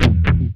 GUITARFX 8-R.wav